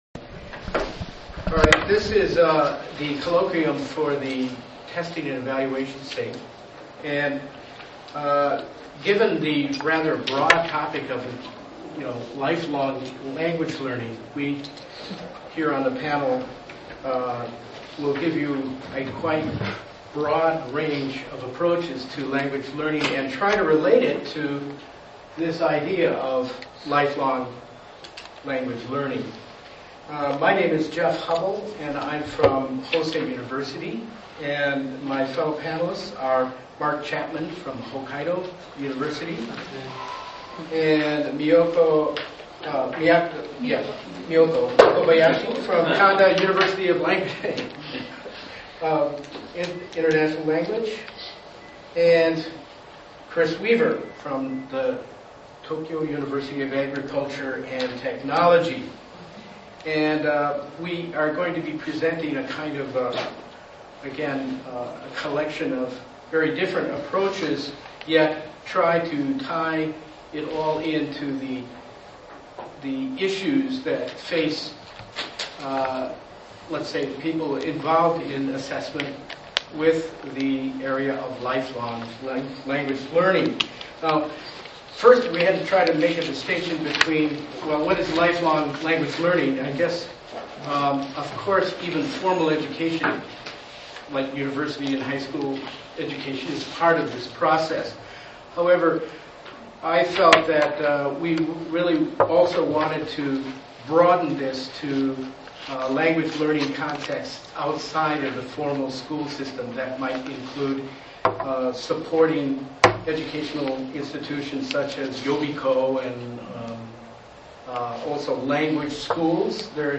Photos & Sound Clips from the JALT 2005 Pan-SIG Conference
TEVAL SIG Panel Opening